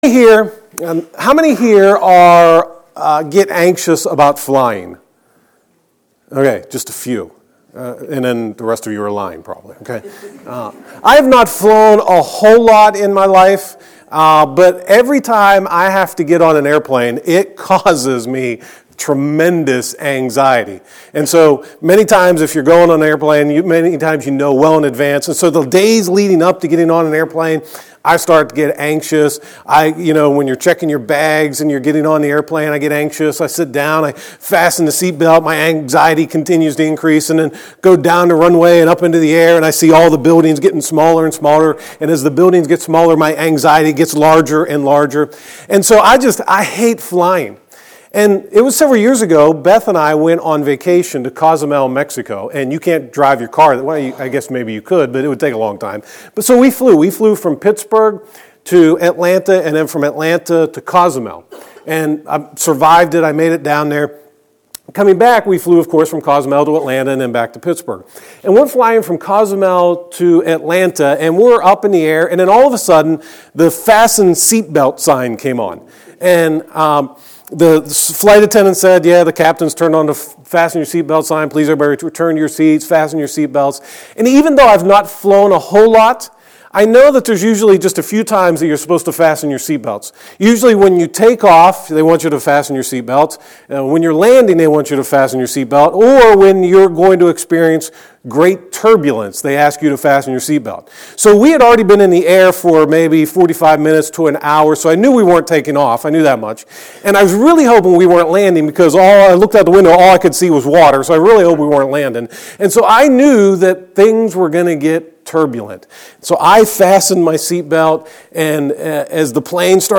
2018 First Things First Preacher